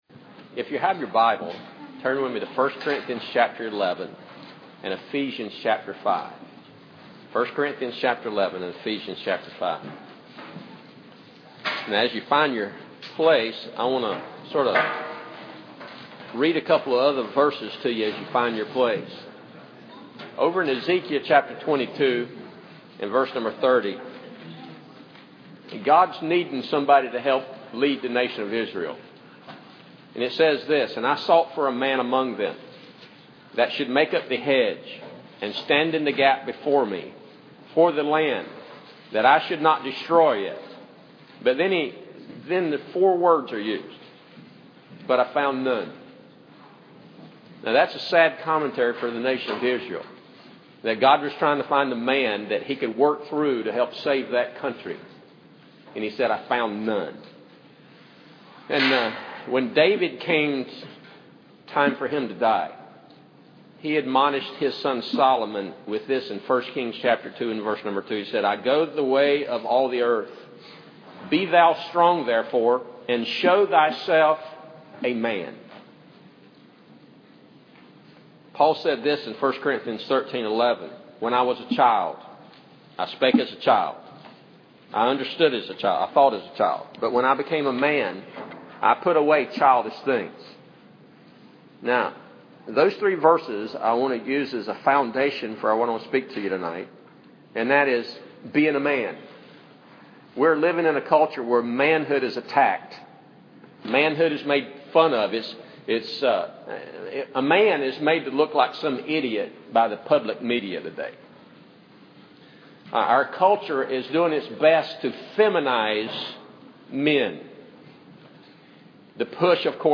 Series: 2017 Family Conference
Service Type: Special Service